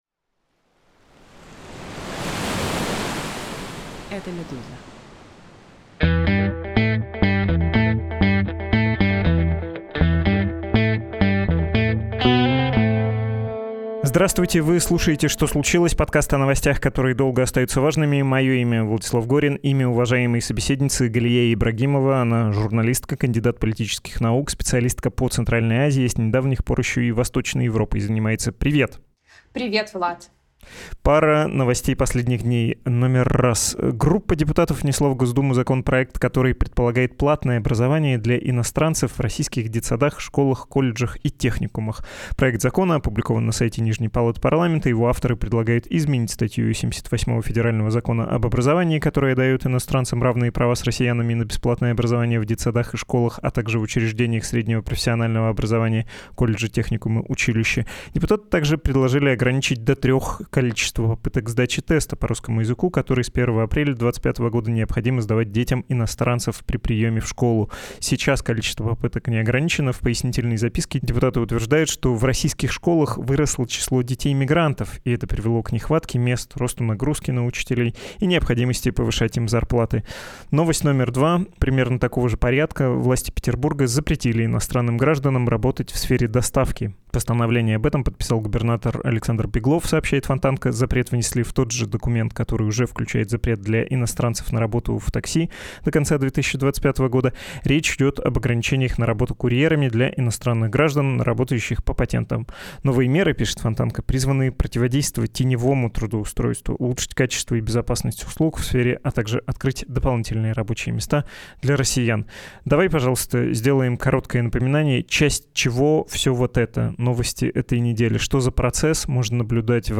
«Что случилось» — новостной подкаст «Медузы».